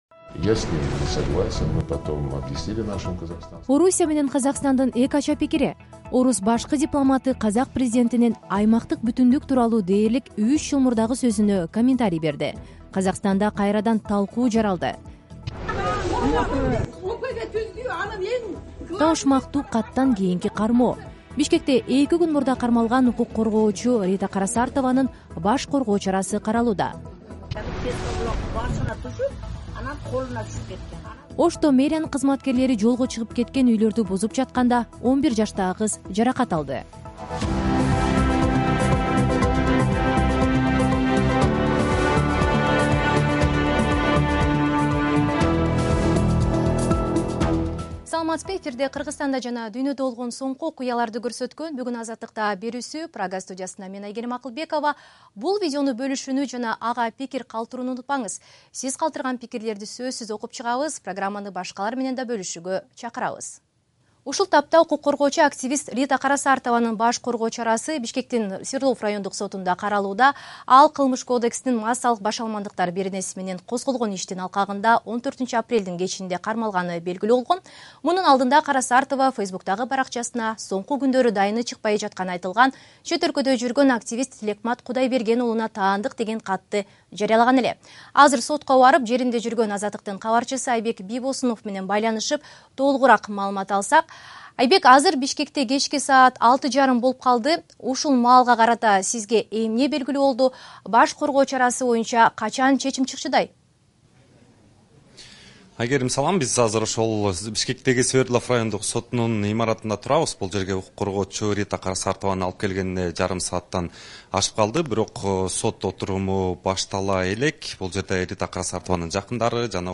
Жаңылыктар | 16.04.2025 | Ош: Үй бузуу маалында 11 жаштагы кыз жаракат алды